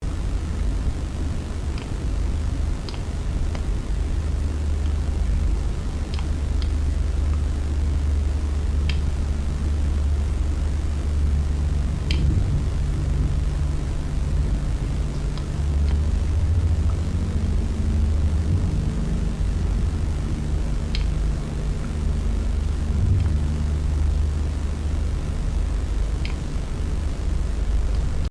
Free Ambient sound effect: Solar Static Ambiance.
Solar Static Ambiance
Solar Static Ambiance.mp3